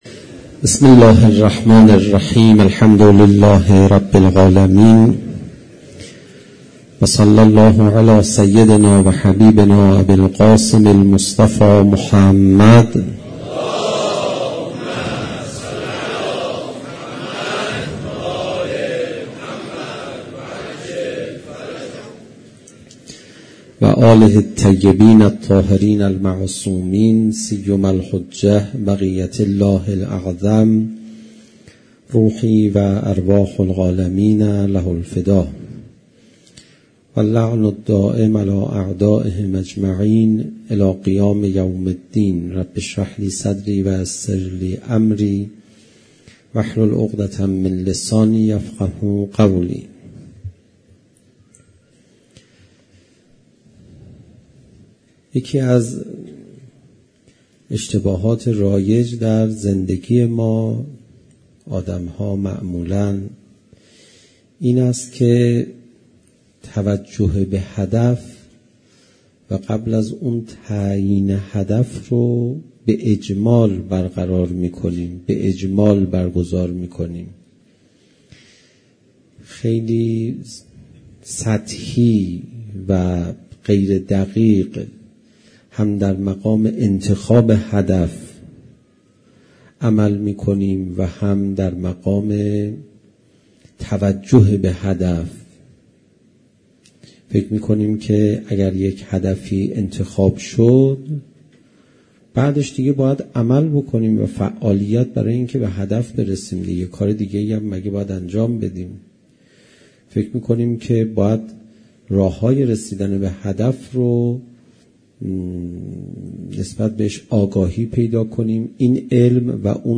صوت: حجه‌الاسلام پناهیان- شب اول محرم
Sokhanrani Shab 1 Moharram 94_0.mp3